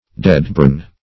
deadborn - definition of deadborn - synonyms, pronunciation, spelling from Free Dictionary
Deadborn \Dead"born`\, a.